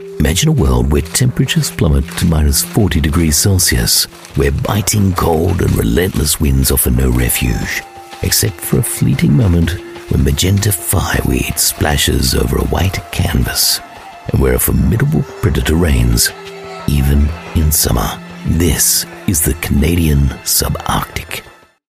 Narration
Neumann TLM 103 mic